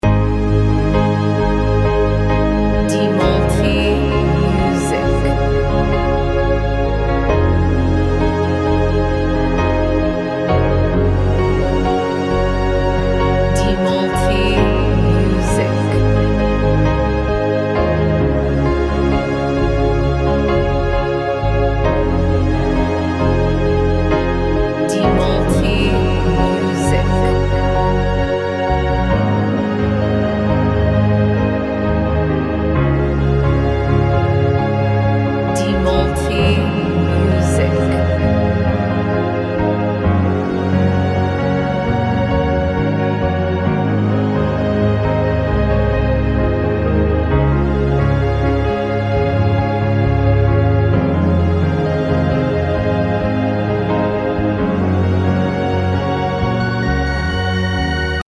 Modern Piano Instrumental